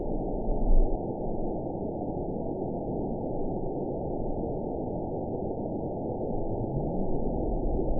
event 922559 date 01/29/25 time 21:41:04 GMT (10 months ago) score 9.39 location TSS-AB10 detected by nrw target species NRW annotations +NRW Spectrogram: Frequency (kHz) vs. Time (s) audio not available .wav